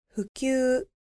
• ふきゅう
• fukyuu